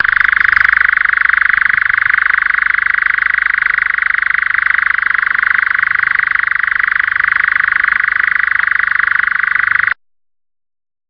sound of the male nightjar and witness its territorial displays.
real_nightjar.rm